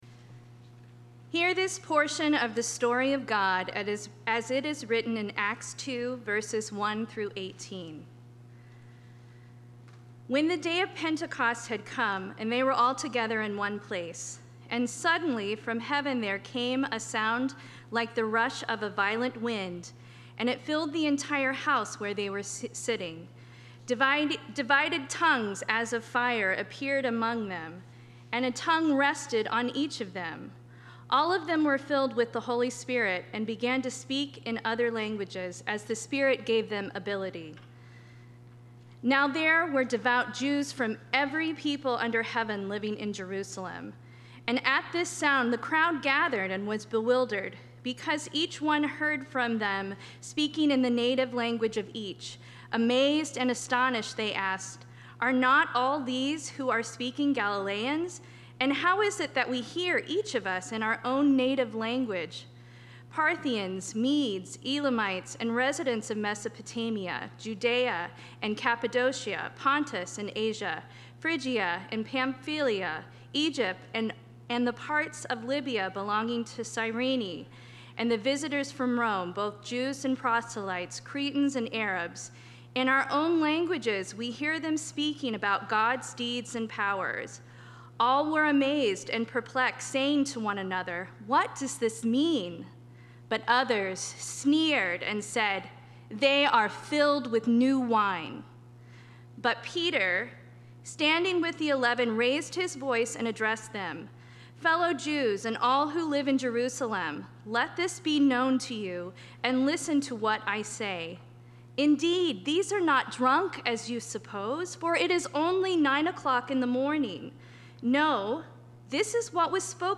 Passage: Acts 2:1-18 Service Type: Sunday Morning